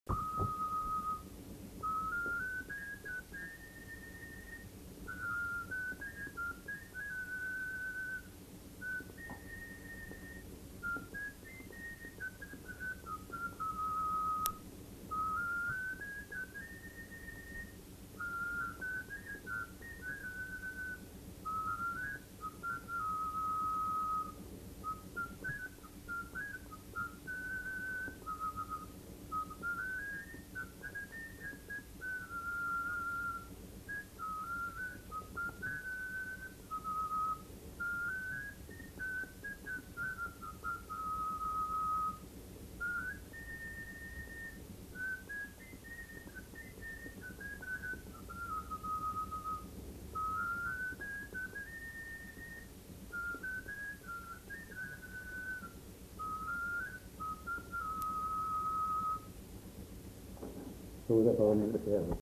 joué à la flûte de Pan et à l'harmonica